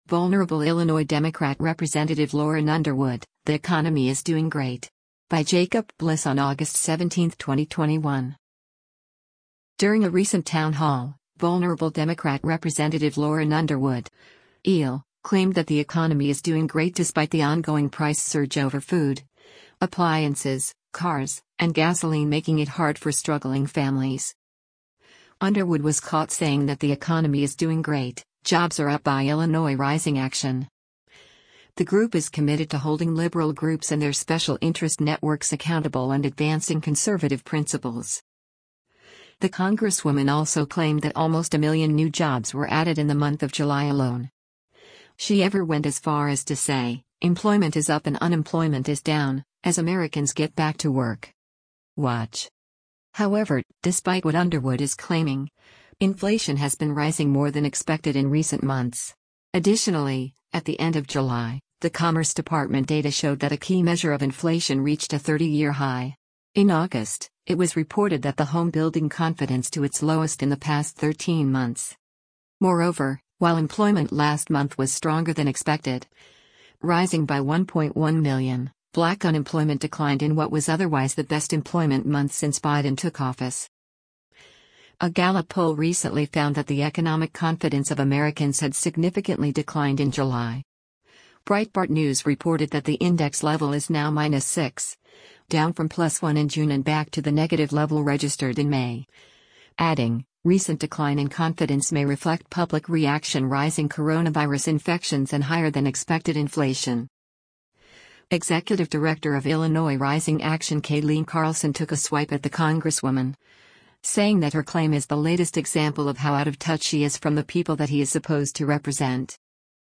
During a recent town hall, vulnerable Democrat Rep. Lauren Underwood (IL) claimed that “the economy is doing great” despite the ongoing price surge over food, appliances, cars, and gasoline making it hard for struggling families.